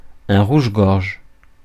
Ääntäminen
IPA: /ʁuʒ.ɡɔʁʒ/